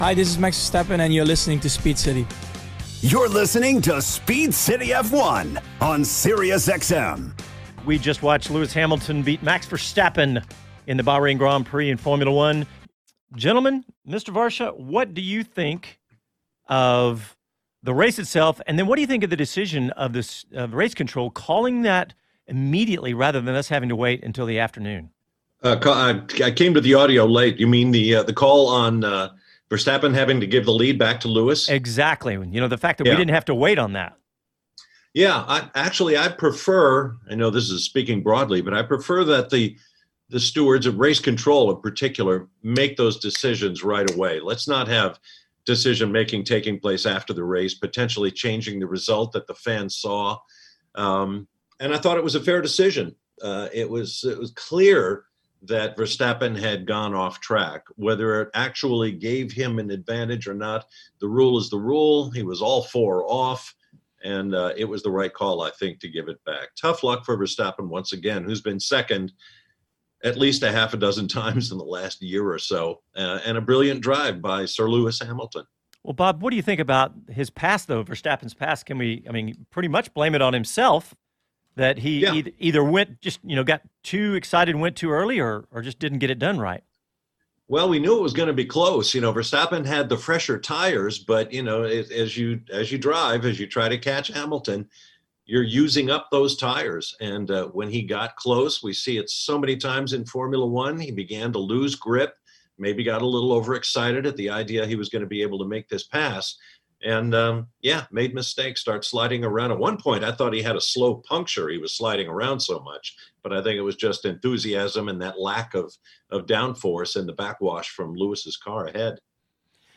Our Formula 1 Bahrain GP post-race show as heard on the ESPN Xtra channel 81 on SiriusXM Sunday morning March 28, 2021. Joining us this year veteran Formula 1 broadcaster Bob Varsha.